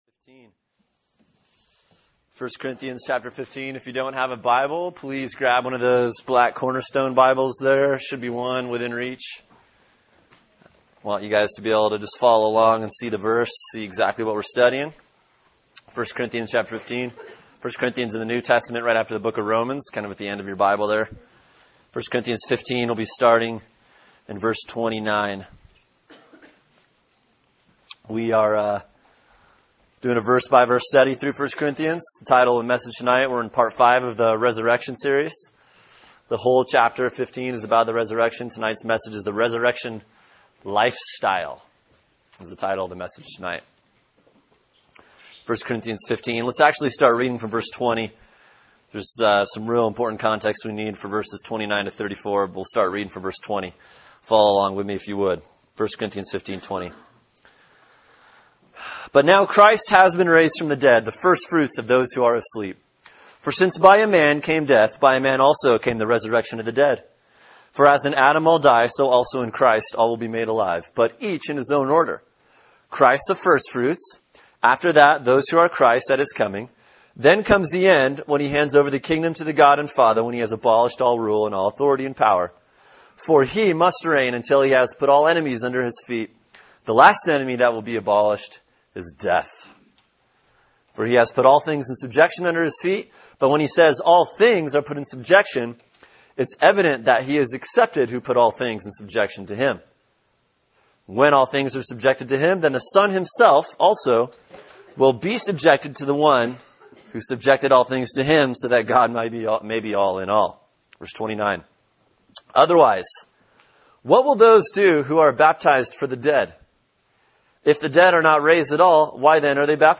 Sermon: 1 Corinthians 15:29-34 “The Resurrection, part 5” | Cornerstone Church - Jackson Hole